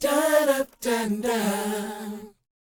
DOWOP D#BD.wav